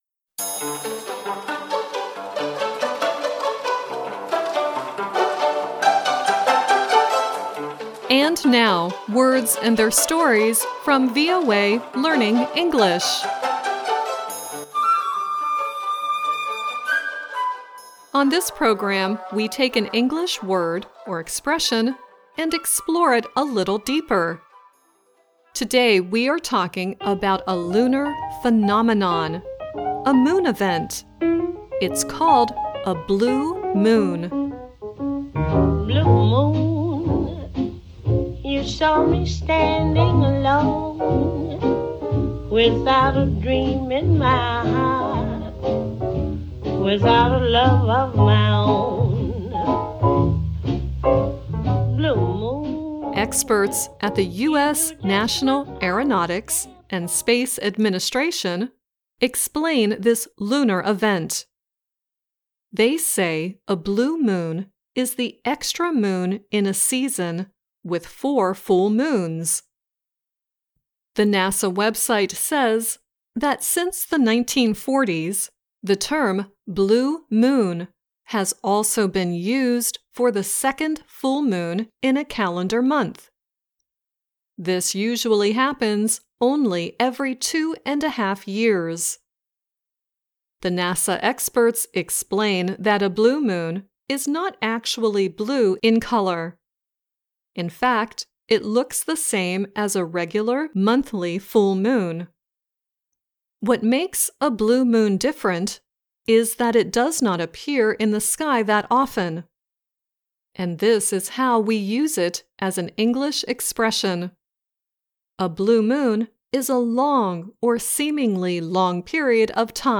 The song at the beginning of the program is Billie Holliday singing "Blue Moon." The song at the end is Patsy Cline singing "Blue Moon."